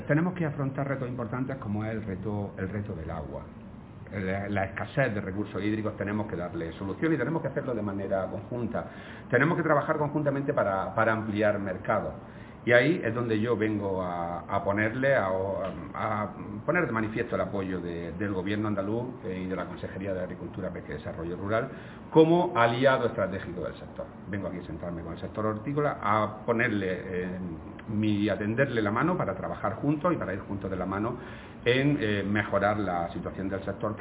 Declaraciones de Rodrigo Sánchez sobre los retos del sector hortofrutícola